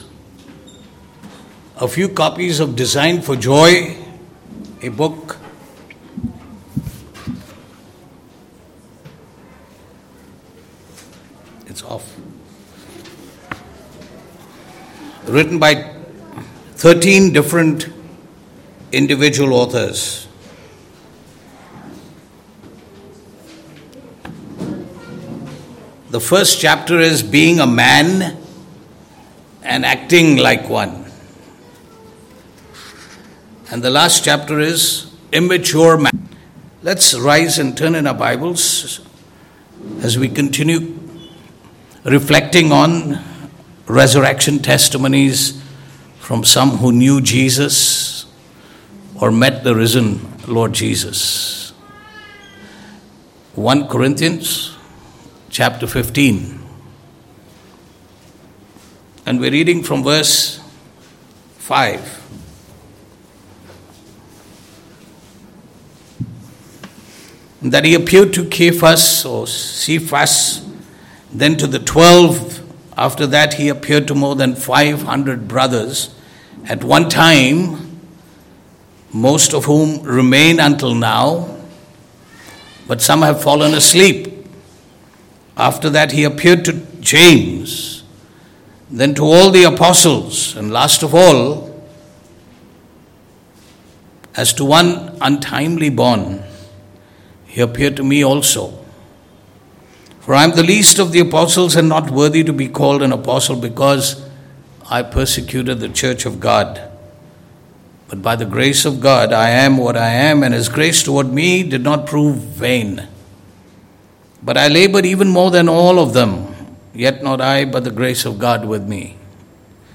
19AprSermon.mp3